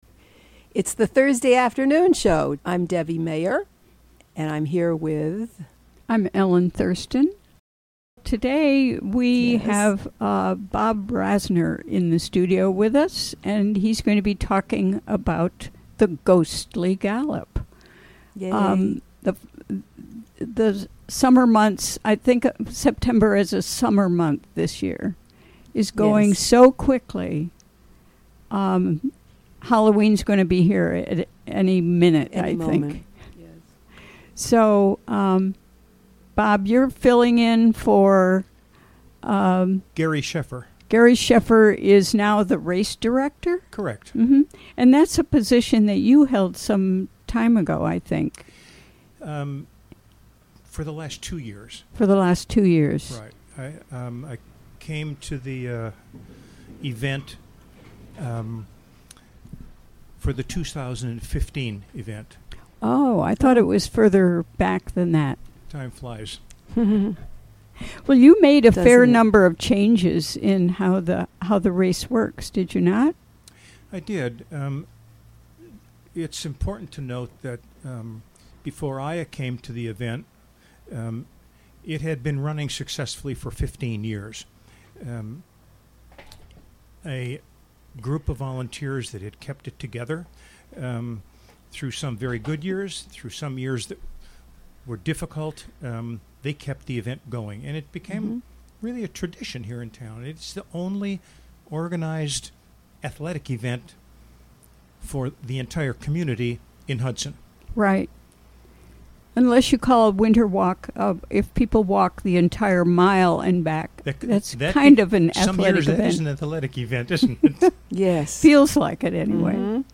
Recorded during the WGXC Afternoon Show on September 21, 2017.